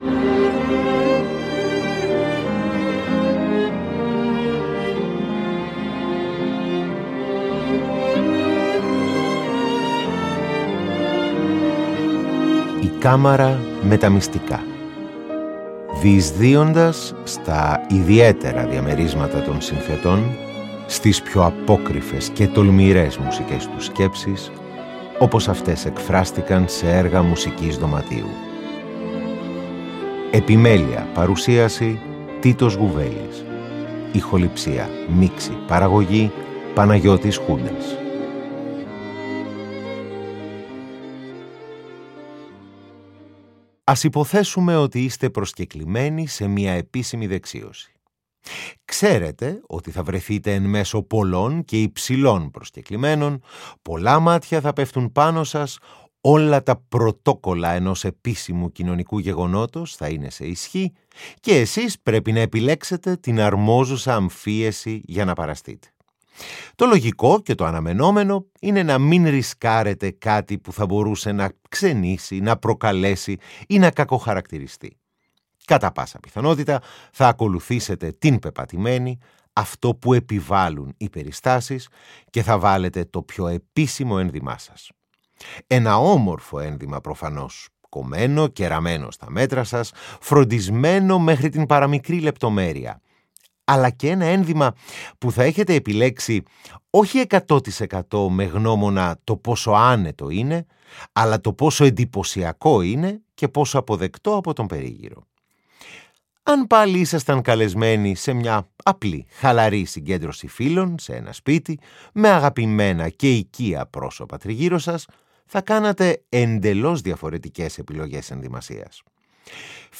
Παρουσιάζονται τέσσερα ιδιαίτερα γοητευτικά έργα ισάριθμων συνθετών, των Λέος Γιάνατσεκ, Γιόζεφ Χάυντν, Ζαν Φρανσαί και Καρλ Ράινεκε, γραμμένα σε τρεις διαφορετικούς αιώνες (18ο, 19ο και 20ο).
Άλλα πιο παθιασμένα και άλλα πιο ανάλαφρα, τα ενώνει η συνθετική μαεστρία και η εξομολογητική διάθεση των δημιουργών τους.